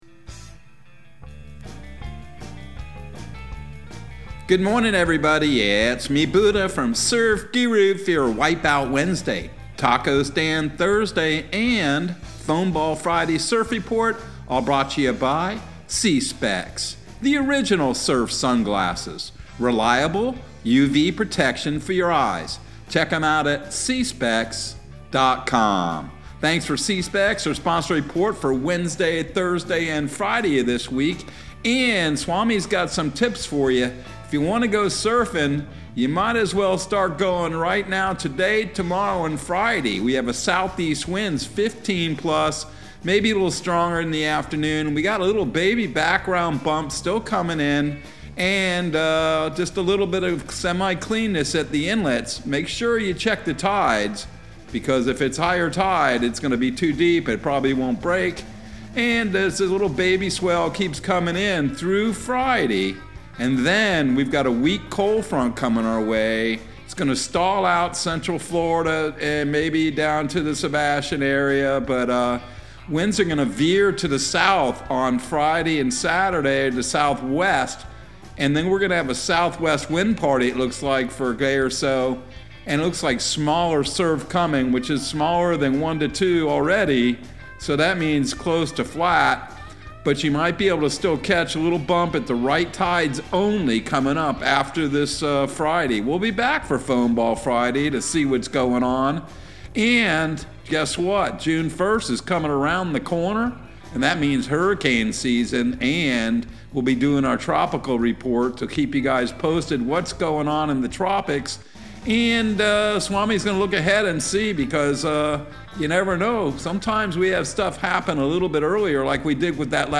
Surf Guru Surf Report and Forecast 05/25/2022 Audio surf report and surf forecast on May 25 for Central Florida and the Southeast.